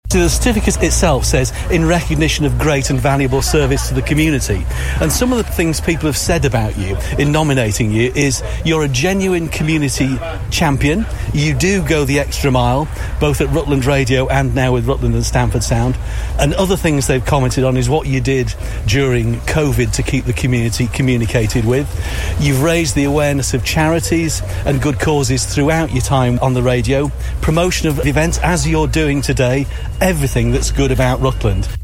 The High Sheriff says: